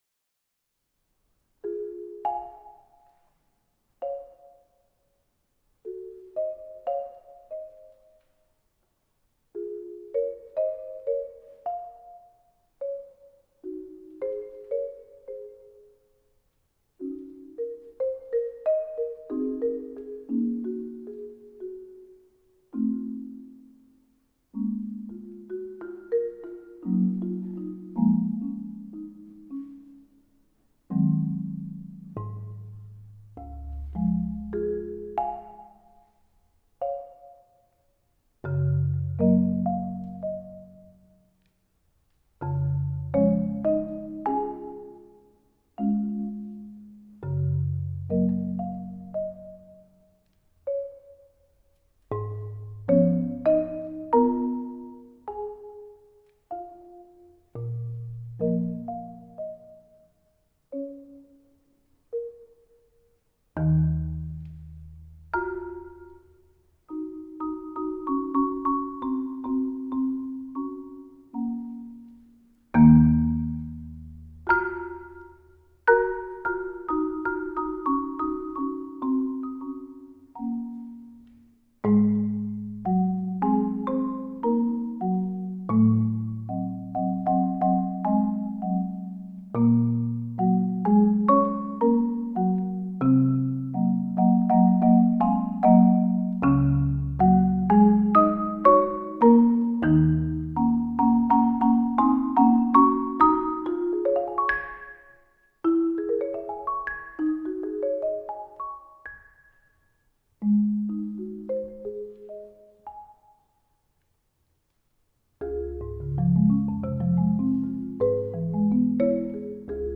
Genre: Solo 4-Mallet Marimba
5-octave Marimba